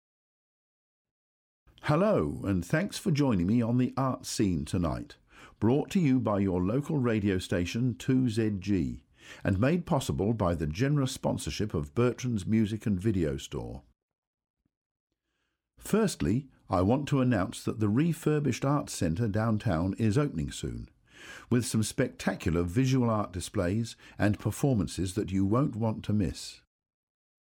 Section 2 is also set in a social context, but this time it’s a monologue – just one person speaking.